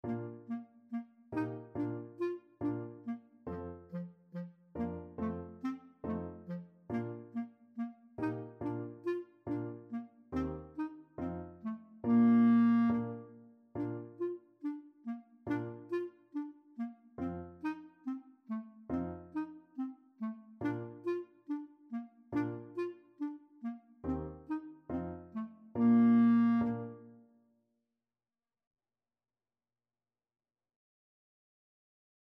Clarinet version
is an English language folk song and nursery rhyme.
Fast = c. 140